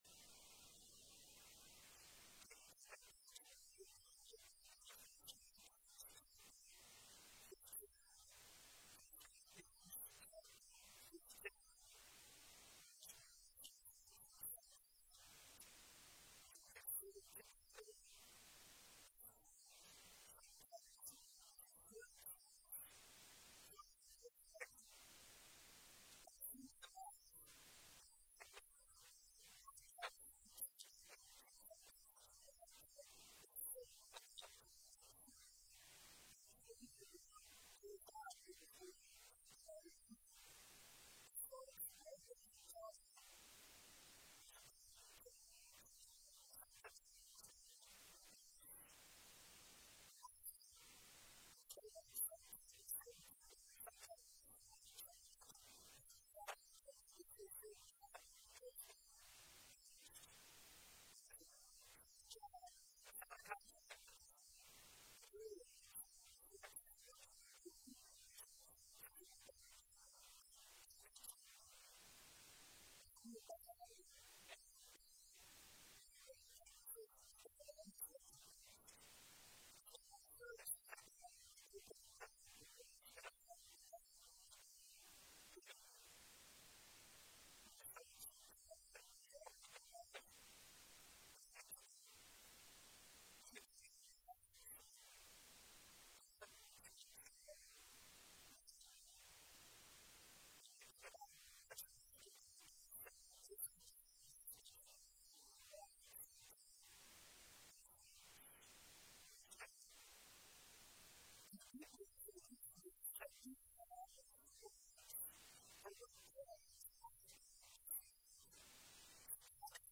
Sermon Library